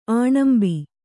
♪ āṇambi